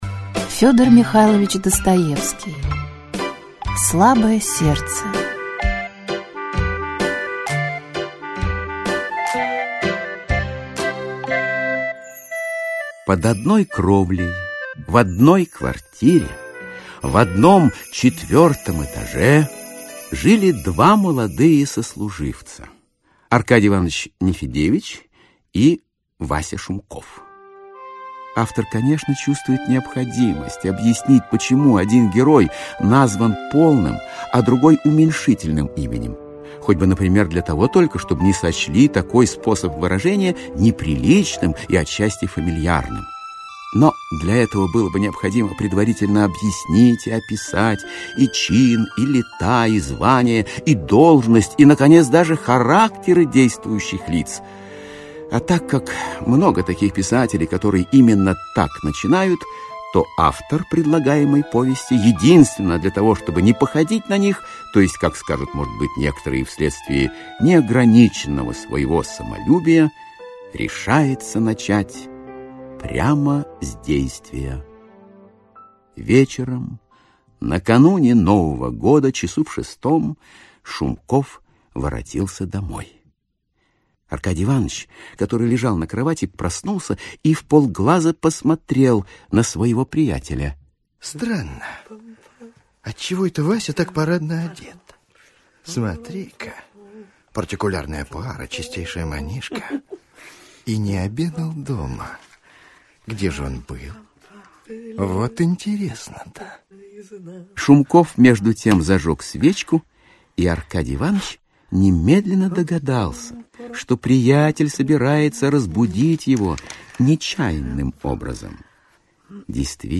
Аудиокнига Слабое сердце. Аудиоспектакль | Библиотека аудиокниг